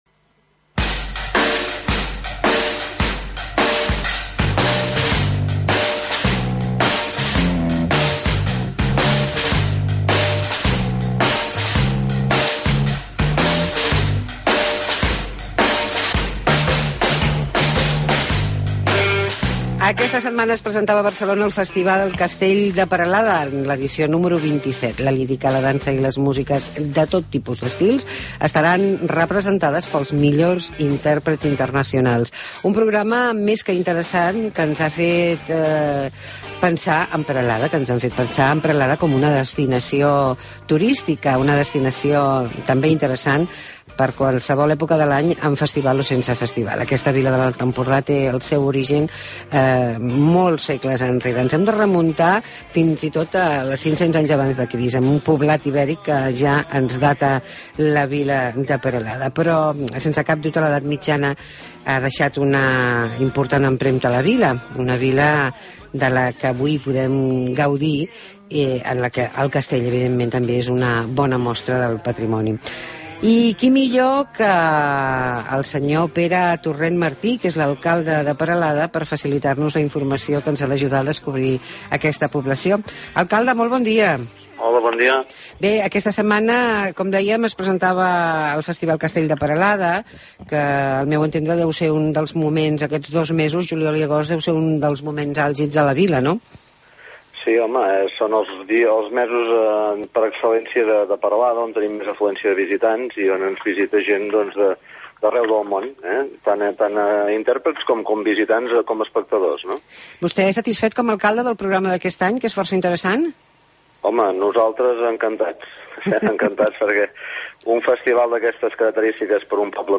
Entrevista amb l'alcalde Pere Torrent sobre el festival del castell de Perelada